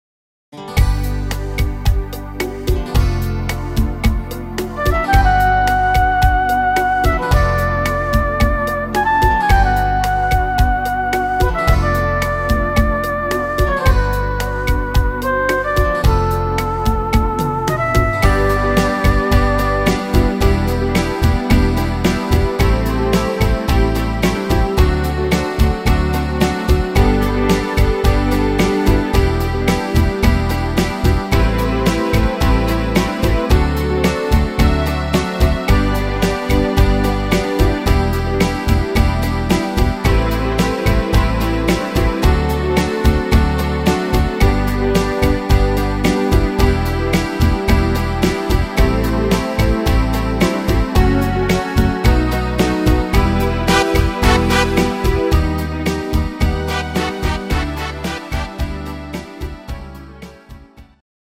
instr.